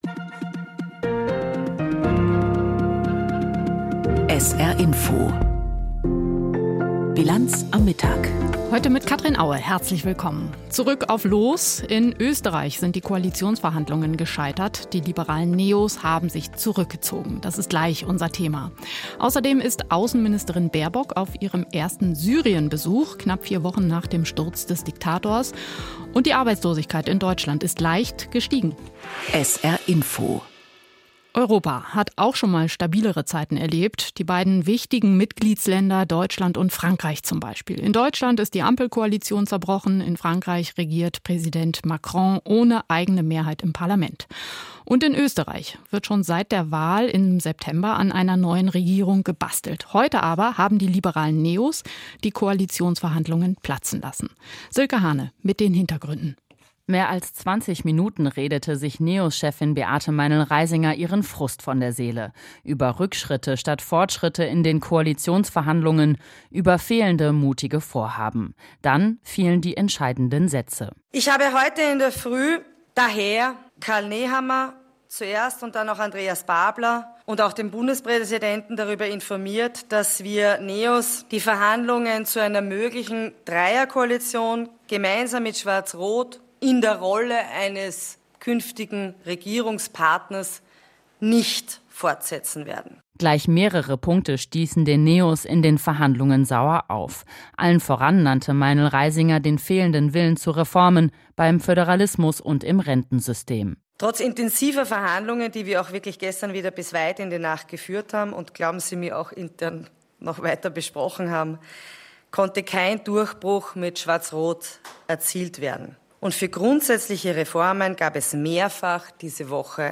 Aktuelles und Hintergründe zu Entwicklungen und Themen des Tages aus Politik, Wirtschaft, Kultur und Gesellschaft in Berichten und Kommentaren.